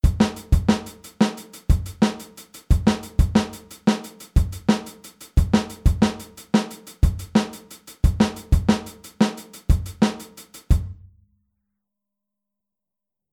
Aufteilung linke und rechte Hand auf HiHat und Snare
Bei diesem Groove haben wir in der Snare eine Dreigruppenaufteilung (ähnlich wie beim Bossa Nova) die du auf jeden Fall auch separat ohne den rechten Fuß üben solltest.